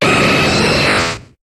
Cri de Sulfura dans Pokémon HOME.